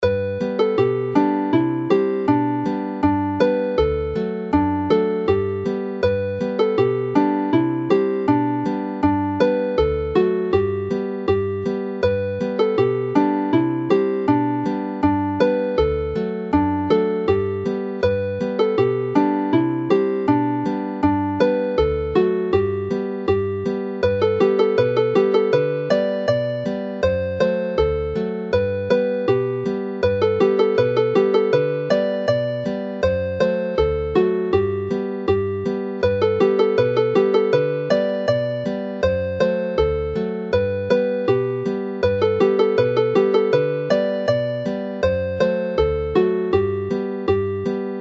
Play the melody slowly